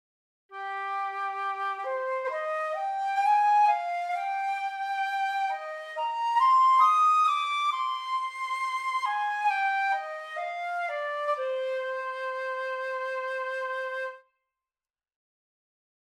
... die EW - Flöte ohne Hall bzw. wenig Hall... die weiche Flöte, von der du schriebst ...
Einmal trocken und einmal mit weniger Reverb als beim letzten Mal. Ganz sauber sind die Übergänge auch bei HOW nicht; ich finde sie recht realistisch und weniger aufdringlich als bei BWW Anhänge how02_dry.mp3 how02_dry.mp3 500,8 KB · Aufrufe: 101 how02_wet.mp3 how02_wet.mp3 500,8 KB · Aufrufe: 103